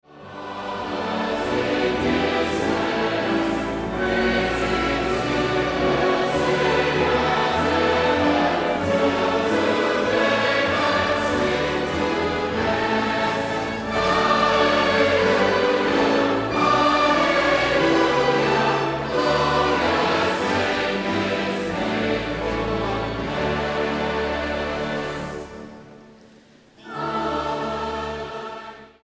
STYLE: Hymnody